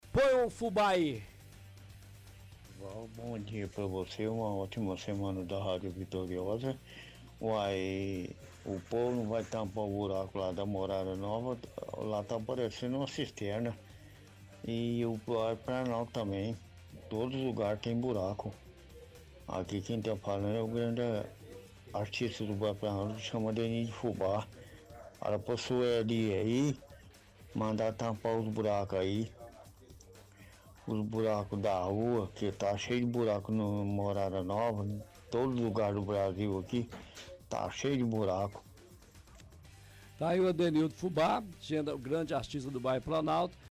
– Ouvinte reclama de buracos no Morada Nova e Planalto.